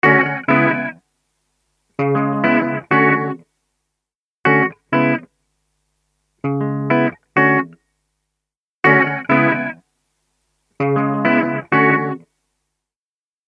TriceraChorusは、サウンドに極上の豊かさと空間的な奥行きをもたらす、洗練されたコーラス・ソリューションです。
TriceraChorus | Electric Guitar | Preset: Chorus Lap
TriceraChorus-Eventide-Guitar-Chorus-Lap.mp3